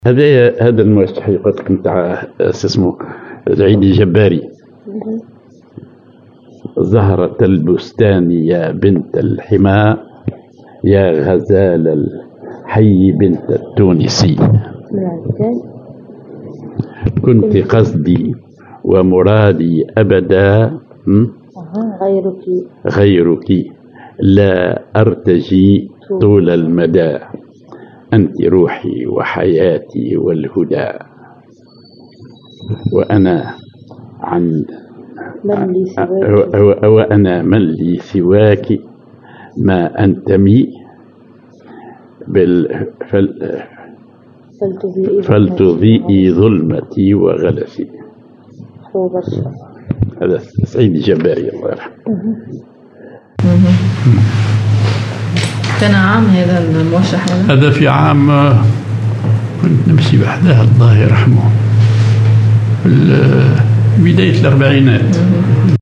genre موشح